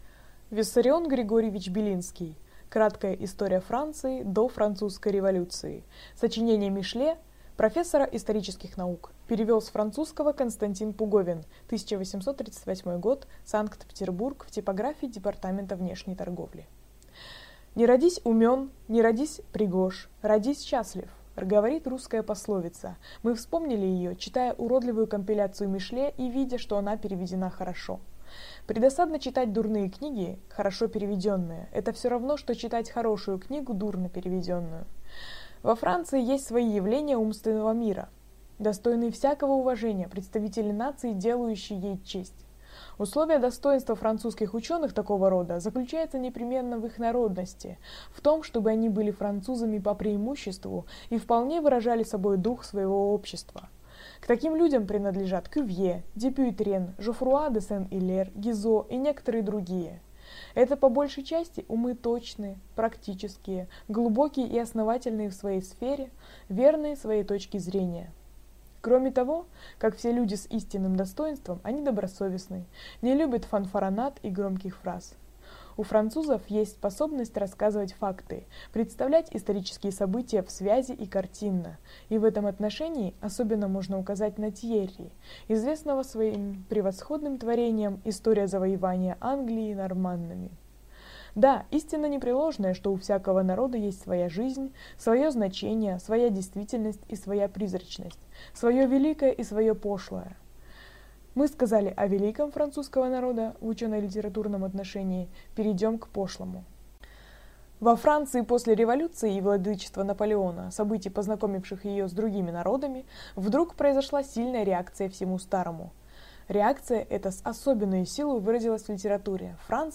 Аудиокнига Краткая история Франции до Французской революции. Сочинение Мишле…